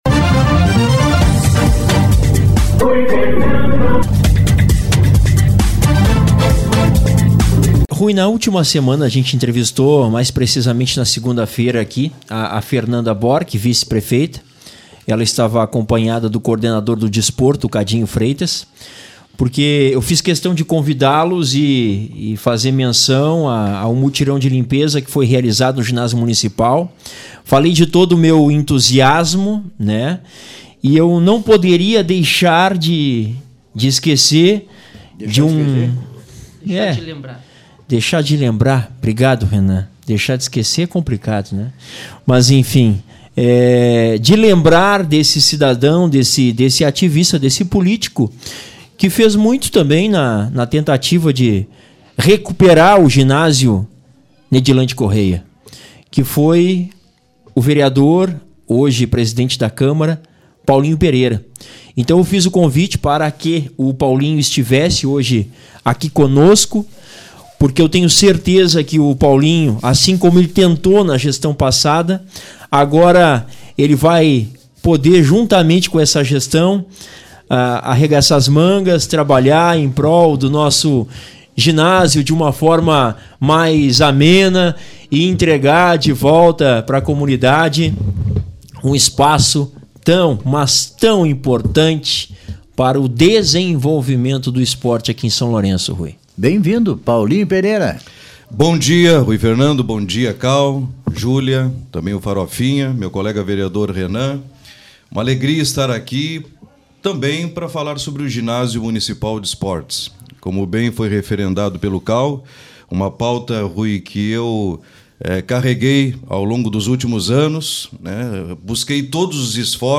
Entrevista com o presidente do Legislativo, Paulinho Pereira e o vereador Renan Hartwig
O presidente do Legislativo, Paulinho Pereira (Podemos), e o vereador Renan Hartwig, estiveram no SLR RÁDIO desta quarta-feira (25) para abordar diversos temas pertinentes à comunidade, dentre: